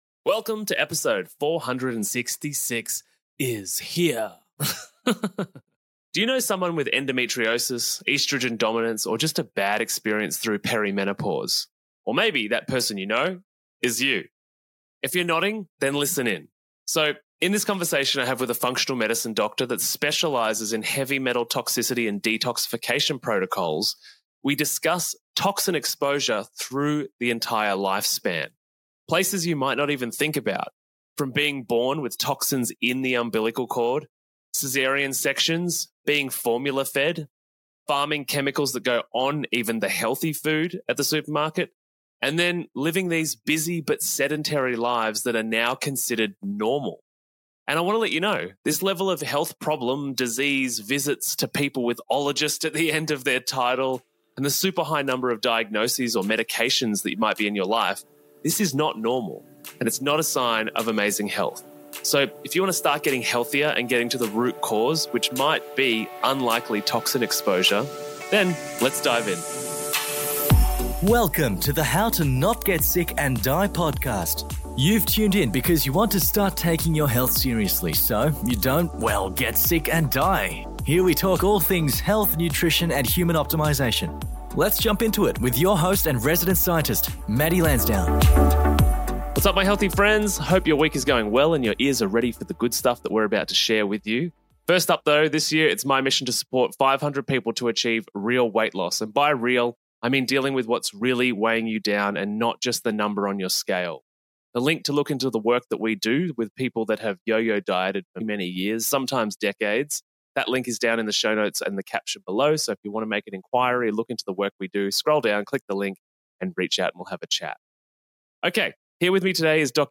From estrogen dominance misdiagnoses to toxic baby formula ingredients, this conversation exposes the gaps in conventional medicine that leave patients suffering for years.